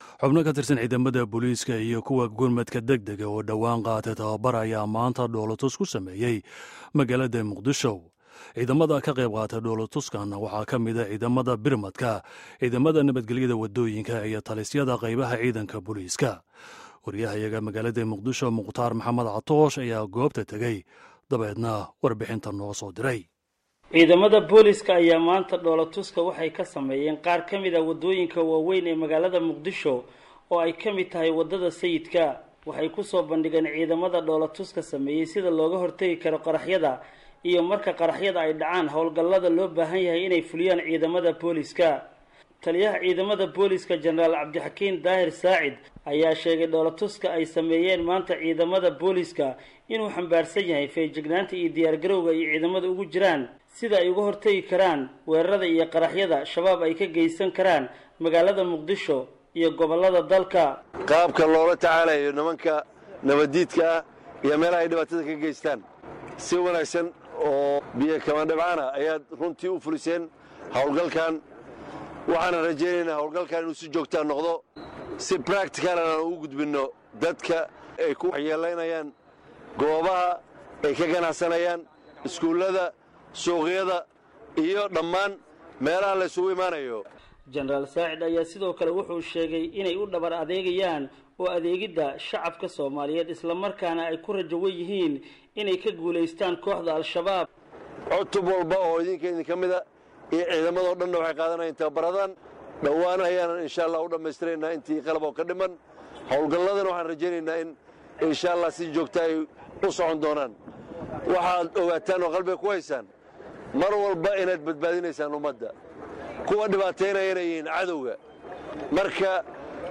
Warbixinta Dhoollatuska Booliiska